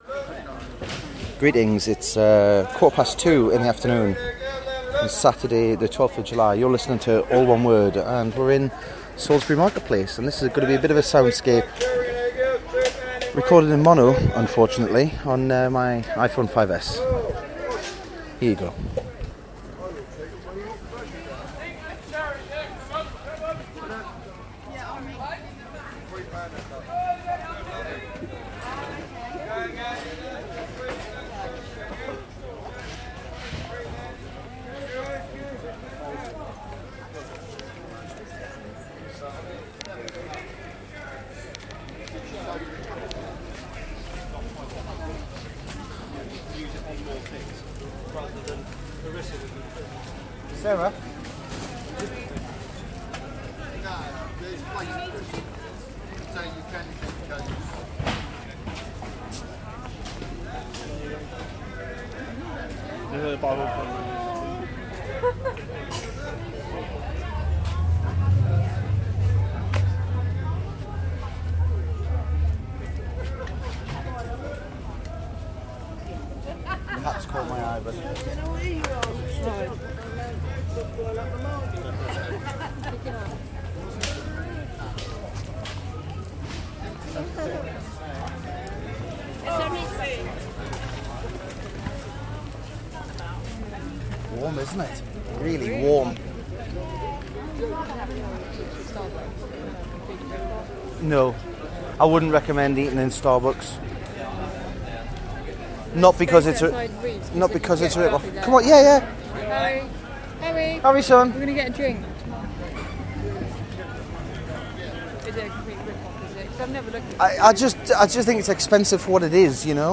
Salisbury Market Place [Soundscape]